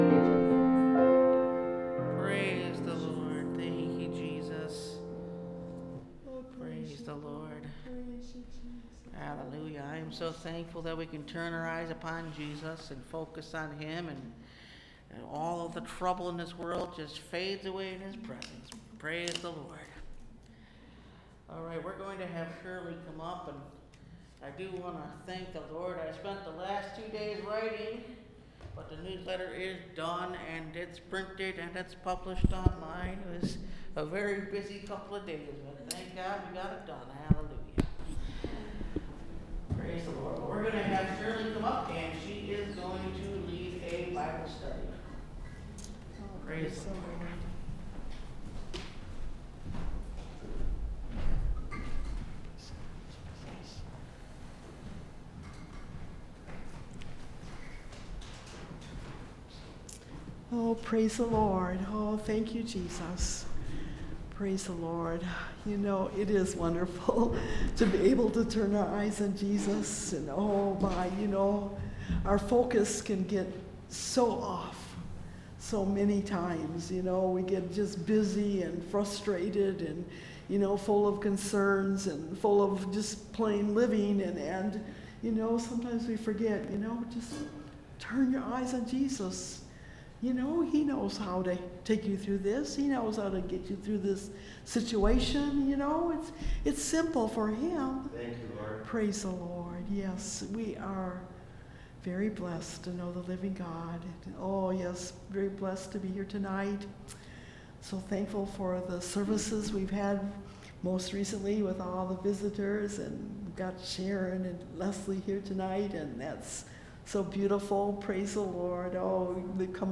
He Is Able To Deliver Thee (Message Audio) – Last Trumpet Ministries – Truth Tabernacle – Sermon Library